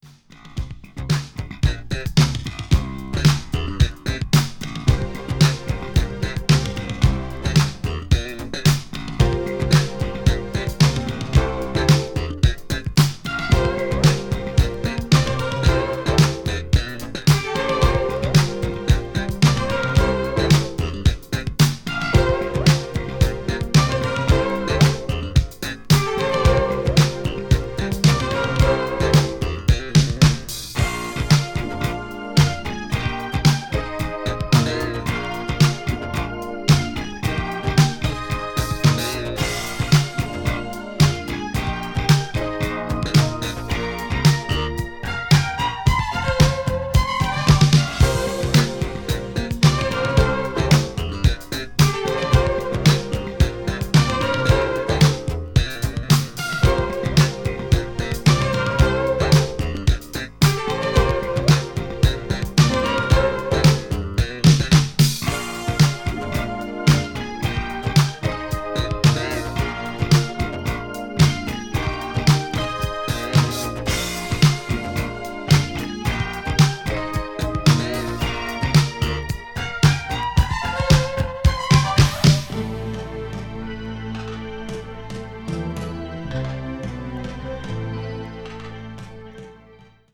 crossover   fusion   jazz groove   obscure dance   synth pop